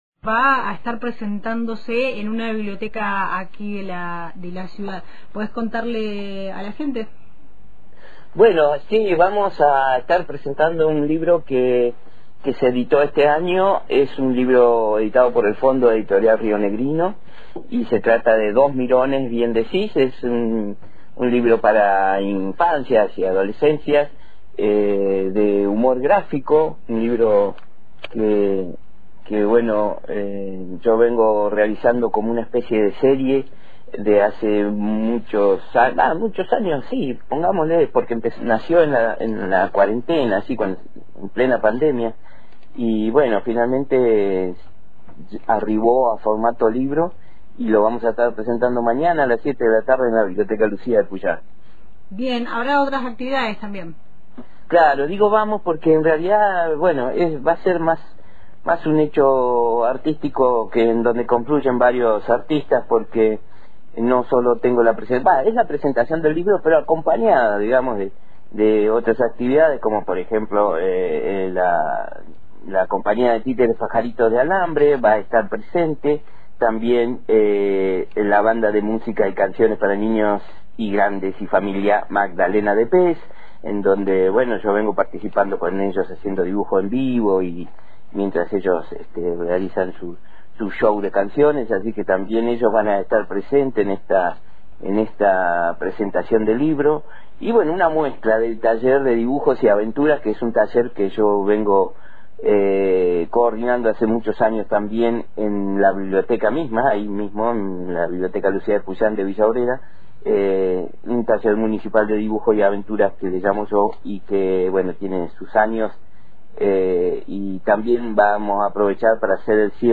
En conversación con Antena Libre